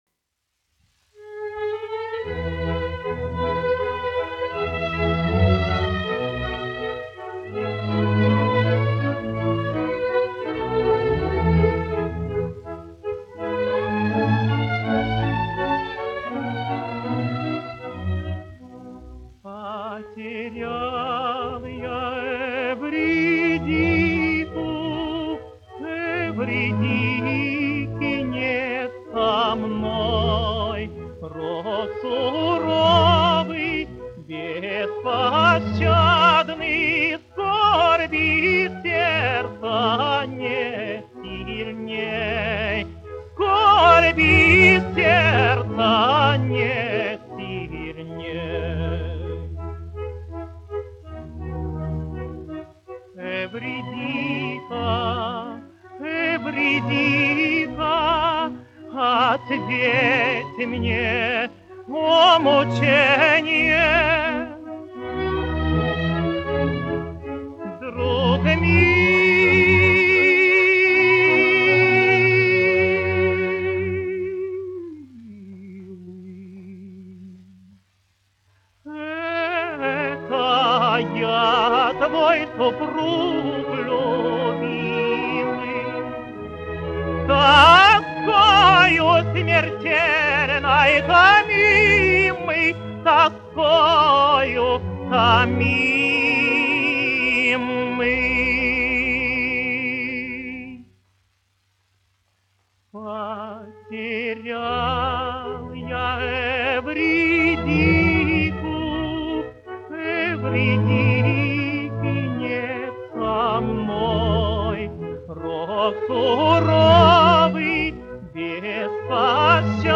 Козловский, Иван Семенович, 1900-1993, dziedātājs
Московская государственная филармония. Симфонический оркестр, izpildītājs
1 skpl. : analogs, 78 apgr/min, mono ; 25 cm
Operas--Fragmenti
Skaņuplate